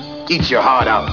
To hear Mr. T answer, just click the responses.